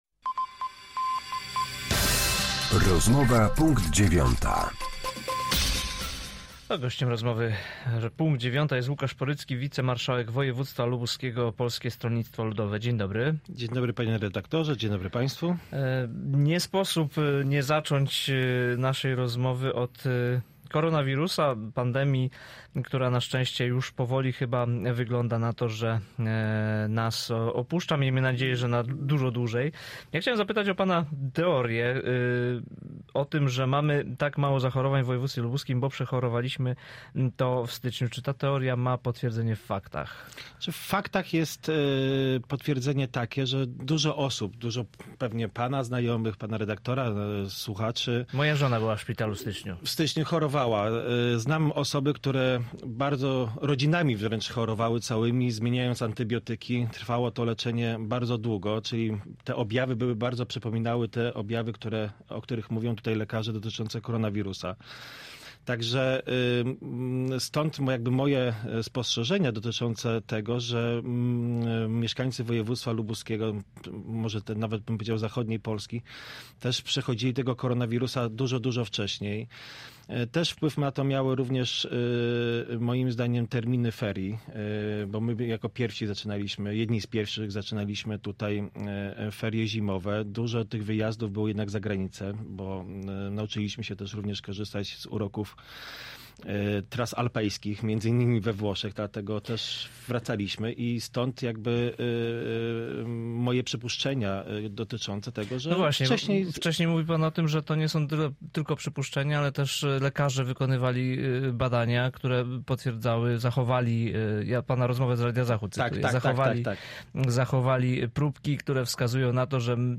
Z wicemarszałkiem województwa lubuskiego rozmawia